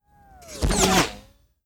zap.wav